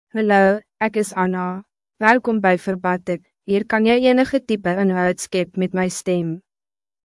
Anna — Female Afrikaans (South Africa) AI Voice | TTS, Voice Cloning & Video | Verbatik AI
Anna is a female AI voice for Afrikaans (South Africa).
Voice sample
Anna delivers clear pronunciation with authentic South Africa Afrikaans intonation, making your content sound professionally produced.